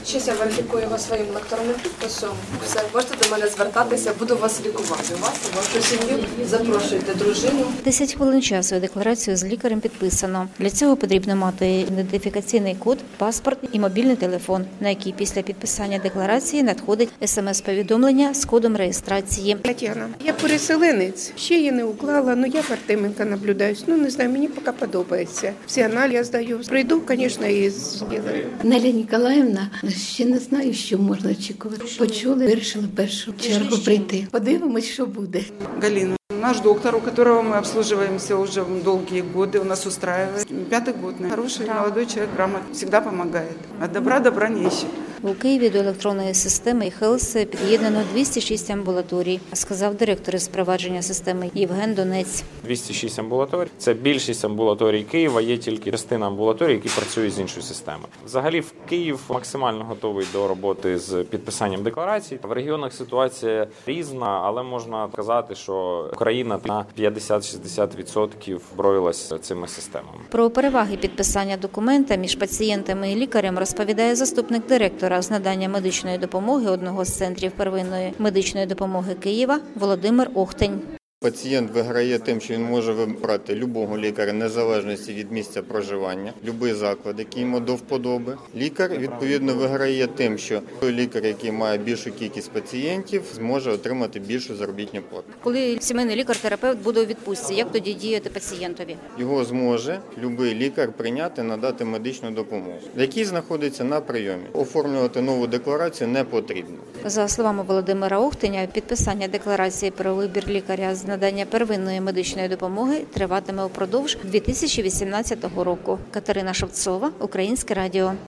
Кампанія з обрання лікаря офіційно розпочалась в Україні (аудіо) | Новини | Українське радіо